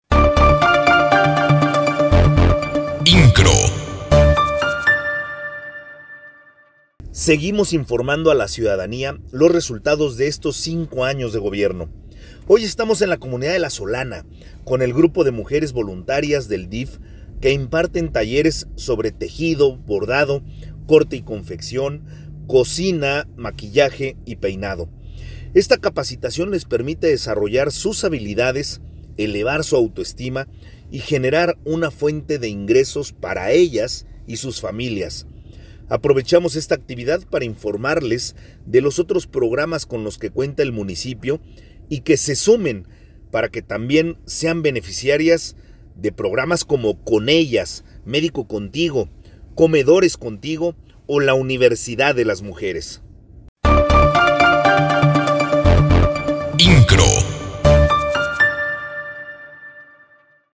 AUDIO-Informa Luis Nava los resultados de cinco años de Gobierno en la comunidad de La Solana – inqro
AUDIO-Informa-Luis-Nava-los-resultados-de-cinco-anos-de-Gobierno-en-la-comunidad-de-La-Solana.m4a